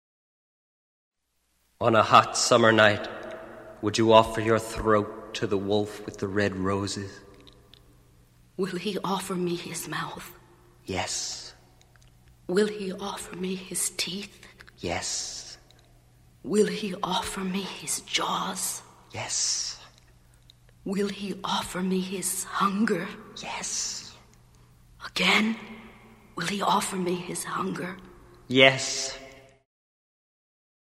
Genere: hard rock